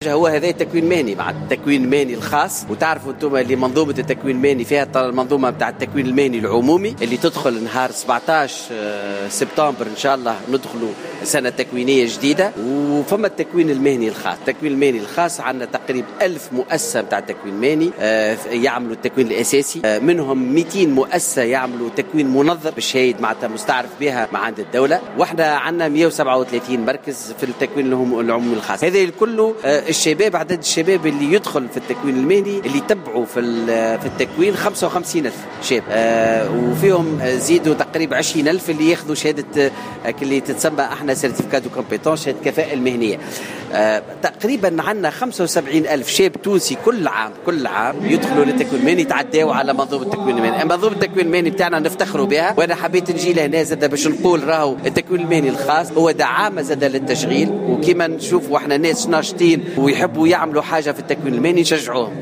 وقال الوزير في تصريح لمراسل لـ"الجوهرة أف أم" على هامش اشرافه اليوم بسوسة على أول عرض للتجميل و الجمال بافريقيا، إن حوالي 75 شاب يمرون بمنظومة التكوين المهني سنويا، مؤكدا أن التكوين المهني الخاص يعتبر دعامة للتشغيل وللإدماج في سوق الشغل.